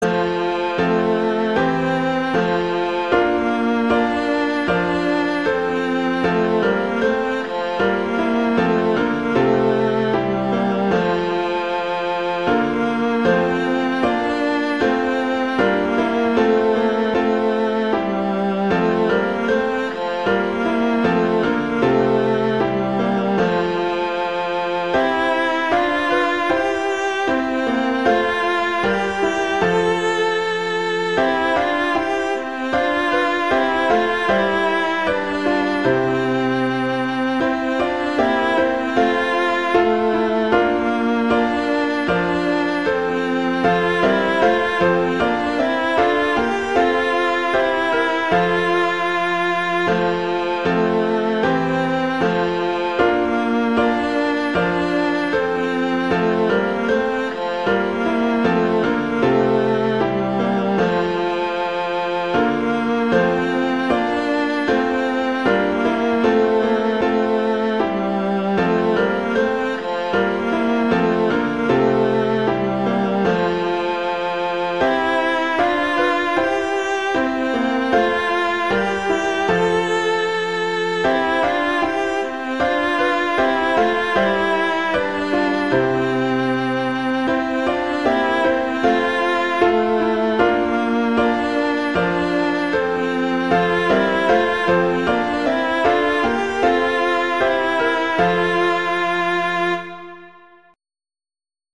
arrangements for viola and piano